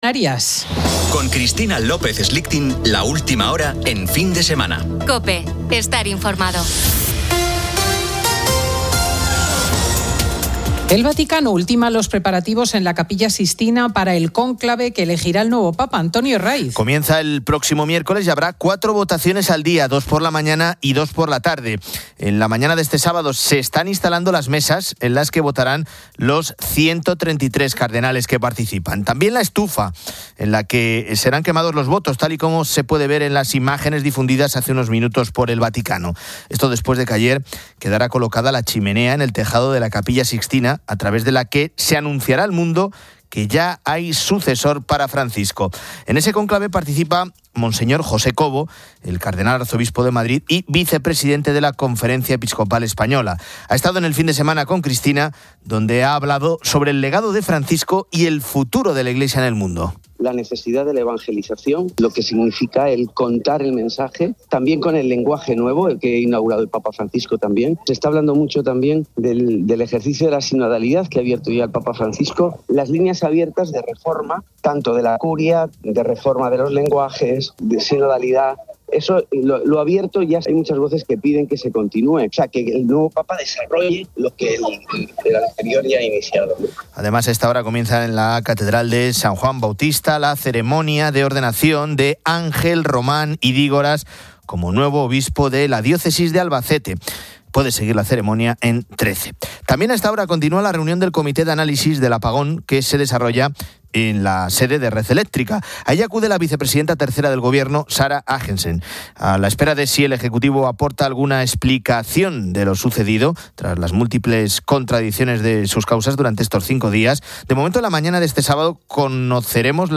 Tertulia de chicos sobre el apagón eléctrico Leer más User Admin 03 may 2025, 12:07 Descargar Facebook Twitter Whatsapp Telegram Enviar por email Copiar enlace